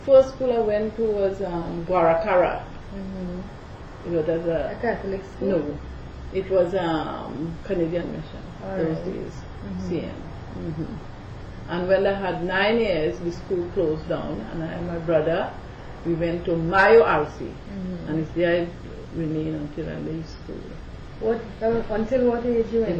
47 audio cassettes